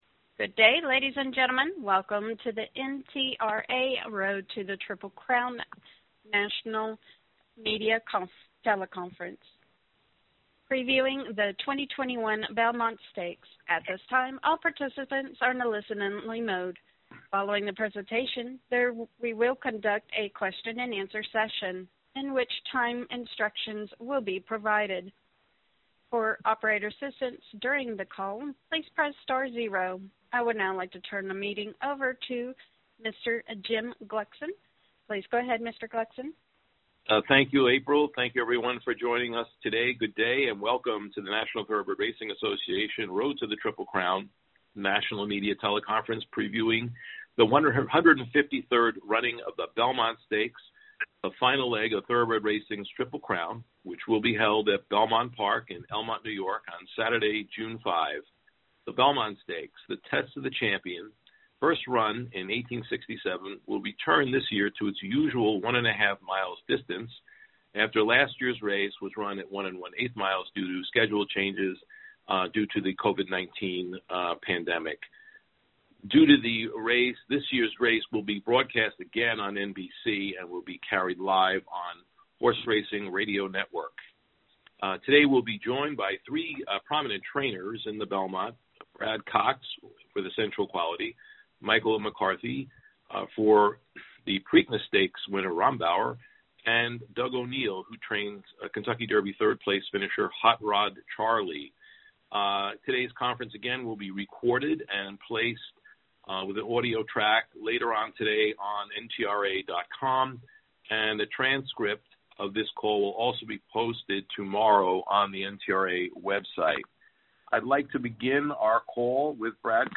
Operator: Good day ladies and gentlemen, welcome to the NTRA Road to the Triple Crown National Media Teleconference previewing the 2021 Belmont Stakes. At this time, all participants are in a listen-only mode.